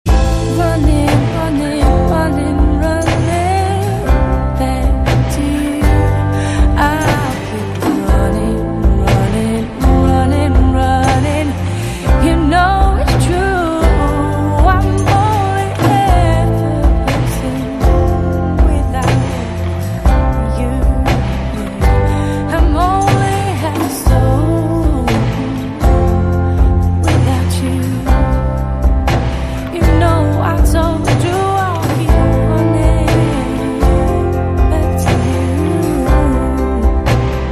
M4R铃声, MP3铃声, 欧美歌曲 84 首发日期：2018-05-15 03:40 星期二